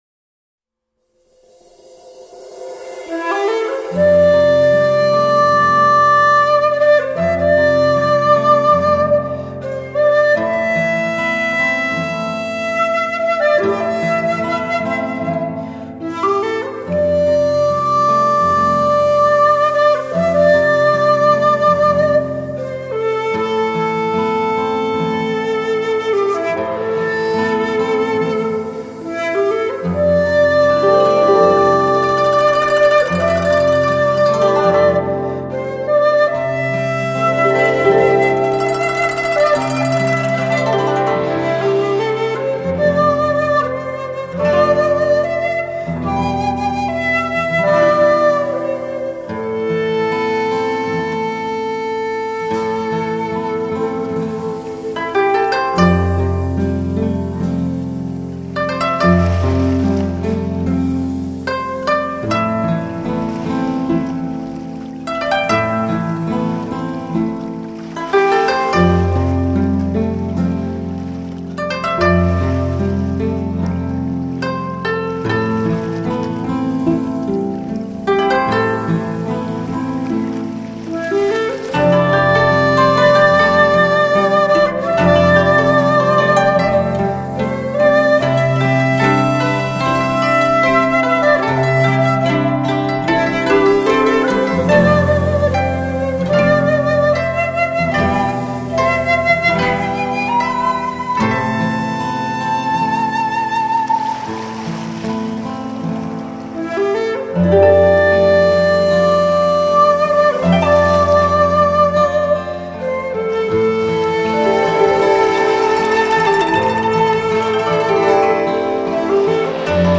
尺八、古筝、吉它三重奏
虽然是以尺八，筝，吉他这些简单的编曲方式，但音节的细胞却如同增殖般产生丰富的变化膨胀。
尺八
筝
ギター